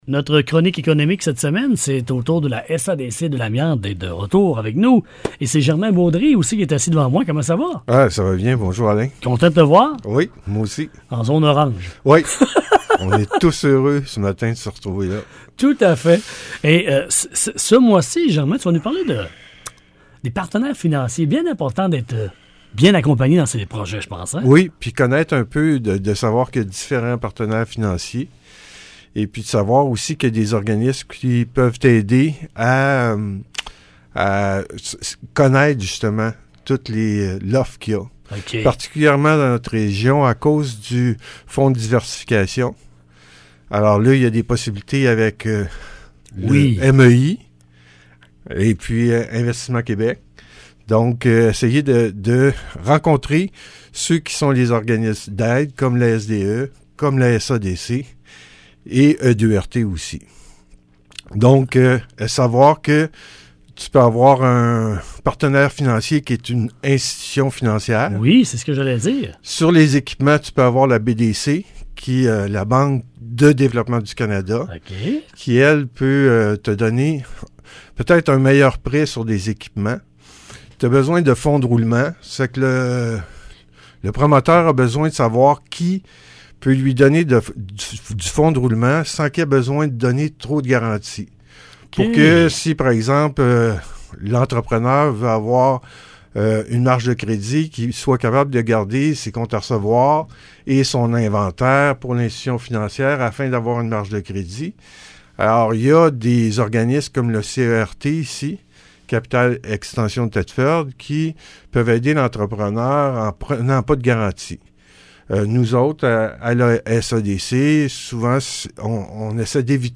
Ce sont 12 chroniques à saveur économique qui ont été diffusées sur les ondes de la station de radio locale :
7-ENTREVUE-SADC-Partenaires-1.mp3